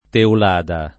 [ teul # da ]